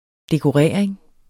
dekorering substantiv, fælleskøn Bøjning -en, -er, -erne Udtale [ degoˈʁεˀɐ̯eŋ ] Betydninger 1.